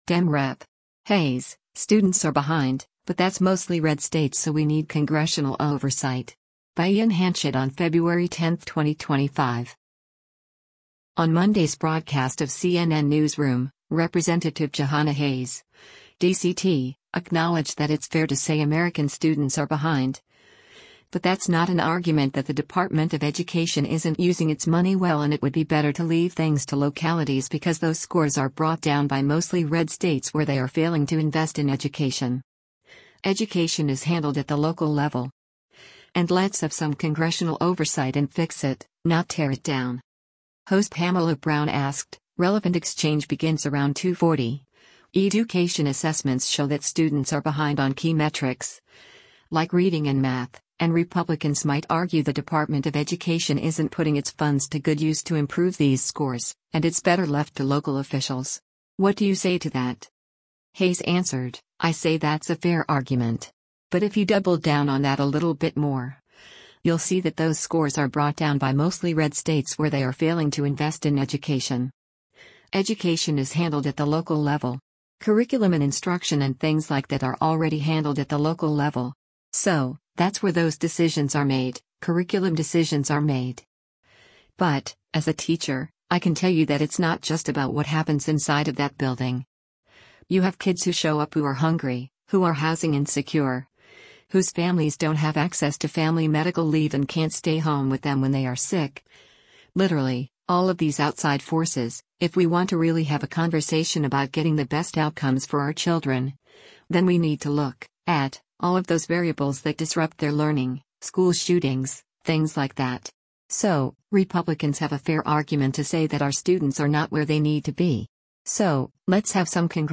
On Monday’s broadcast of “CNN Newsroom,” Rep. Jahana Hayes (D-CT) acknowledged that it’s fair to say American students are behind, but that’s not an argument that the Department of Education isn’t using its money well and it would be better to leave things to localities because “those scores are brought down by mostly red states where they are failing to invest in education.